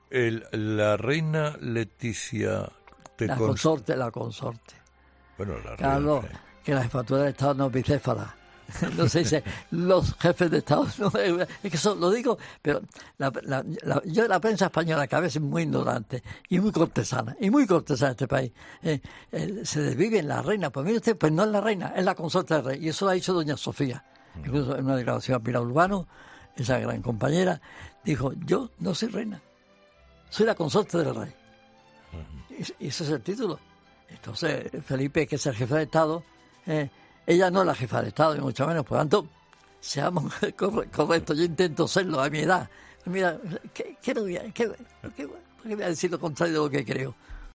"La Reina Letizia...", es como comenzaba Carlos Herrera a preguntarle a Jaime Peñafiel en la entrevista que concedía este miércoles el veterano periodista en 'Herrera en COPE'.